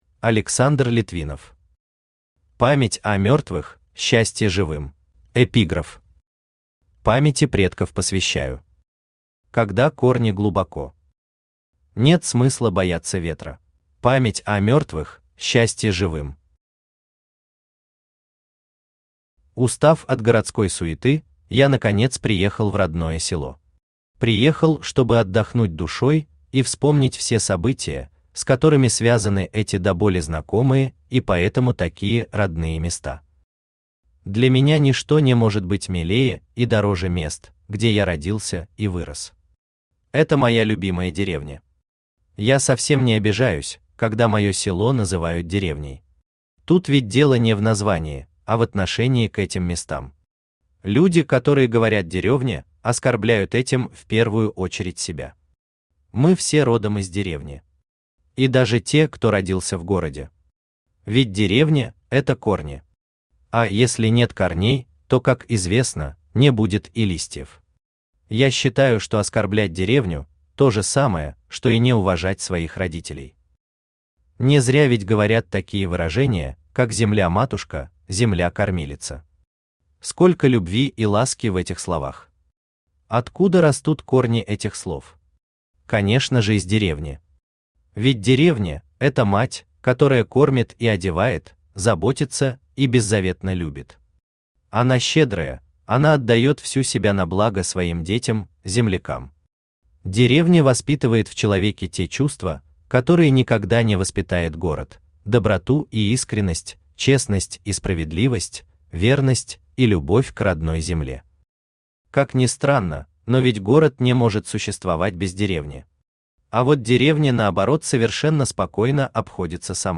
Аудиокнига Память о мертвых – счастье живым | Библиотека аудиокниг
Aудиокнига Память о мертвых – счастье живым Автор Александр Литвинов Читает аудиокнигу Авточтец ЛитРес.